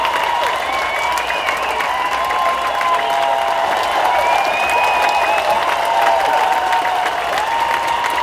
1 channel
FANFARE.WAV